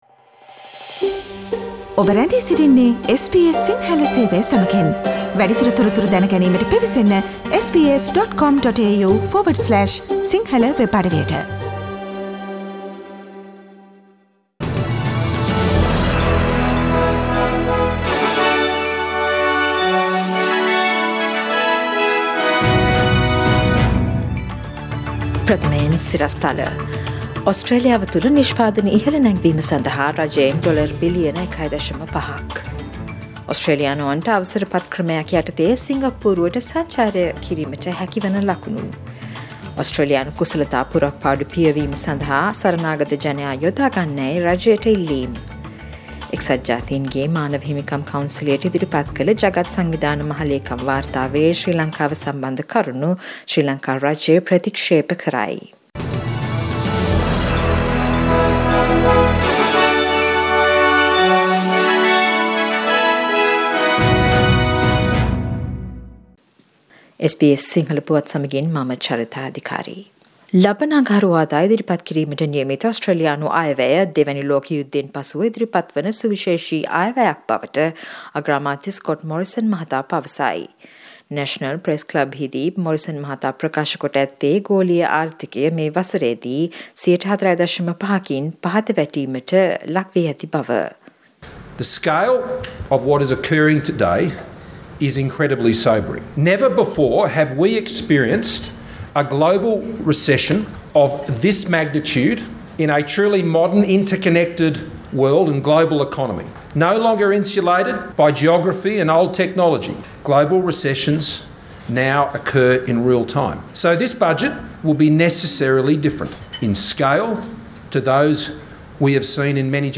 Daily News bulletin of SBS Sinhala Service: 2nd October 2020